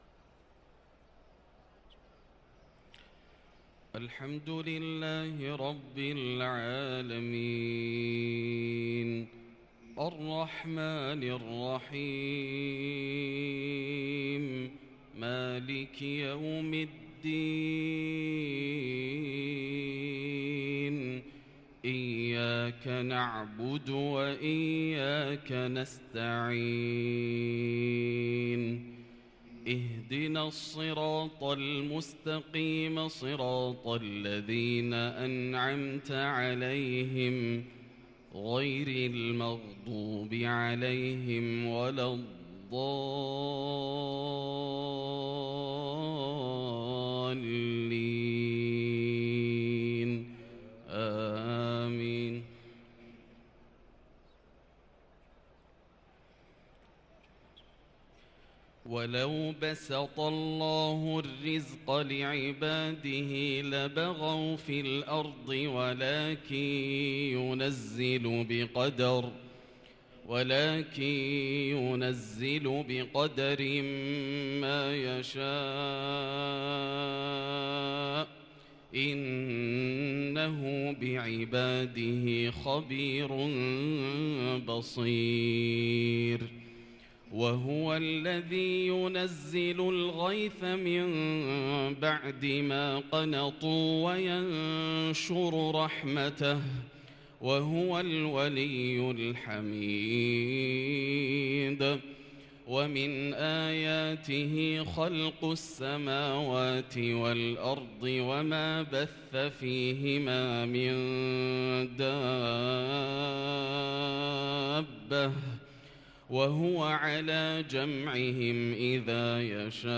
صلاة الفجر للقارئ ياسر الدوسري 17 ربيع الآخر 1444 هـ
تِلَاوَات الْحَرَمَيْن .